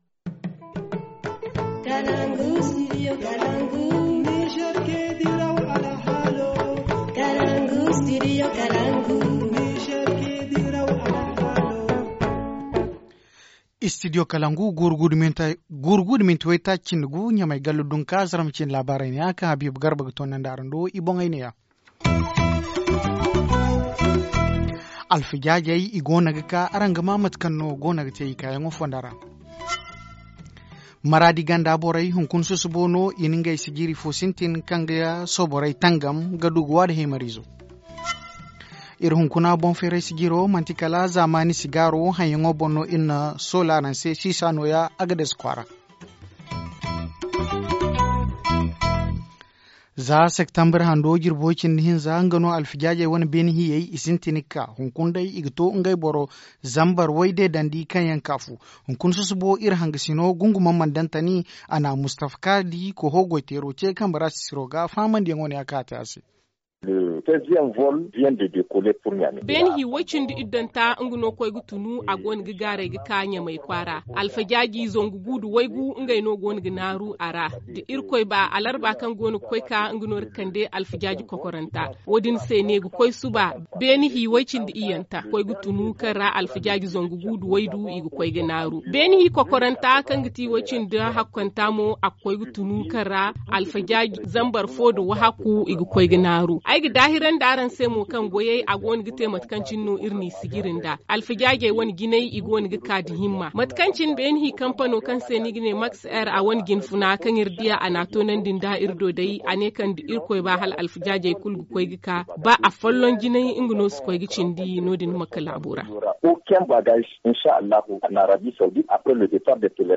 Journal du 25 septembre 2017 - Studio Kalangou - Au rythme du Niger